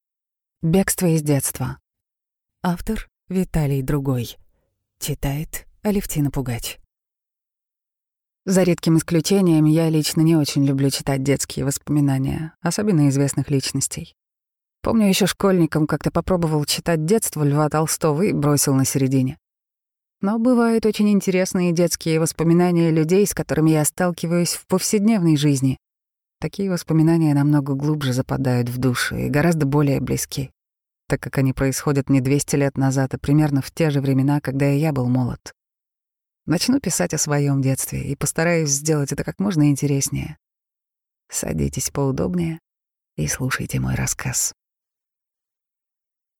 Аудиокнига Бегство из детства | Библиотека аудиокниг